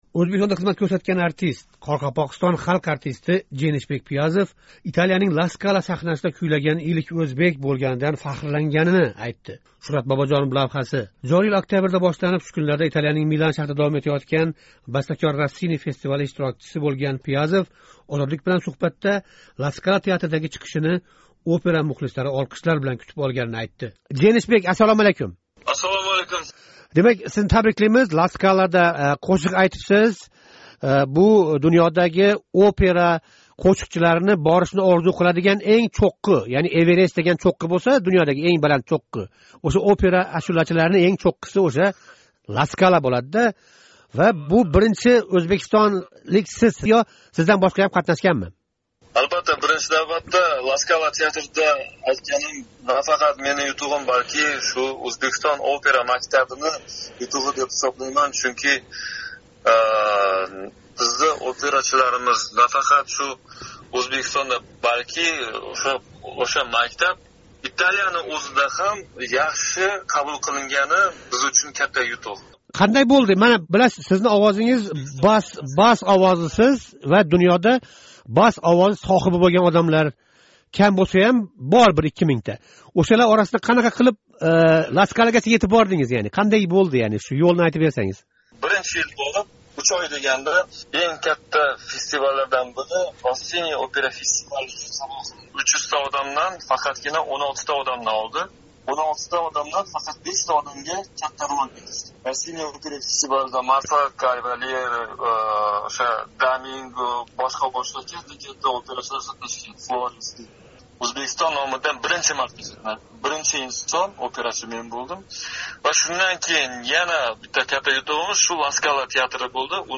Майсаранинг иши операсидан ария